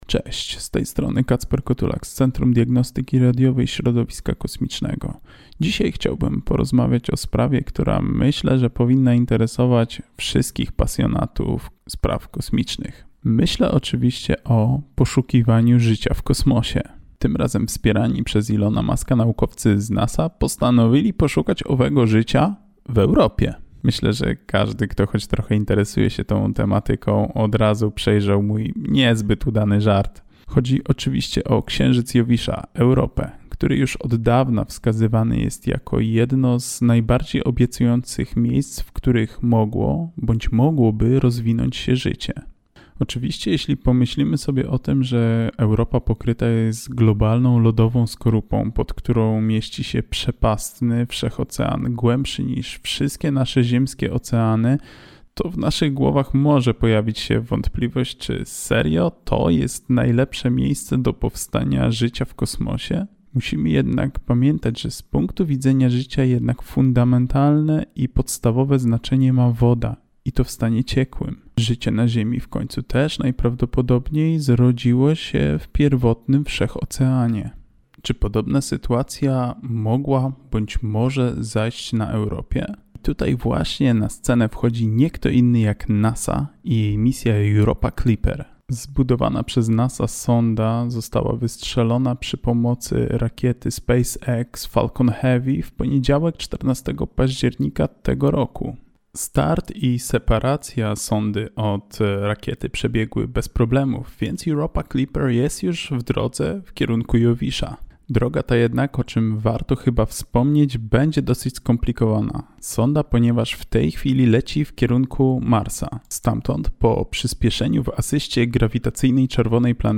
Postój z Nauką robimy sobie na antenie Radia UWM FM od poniedziałku do czwartku około 14:15 w audycji Podwójne Espresso.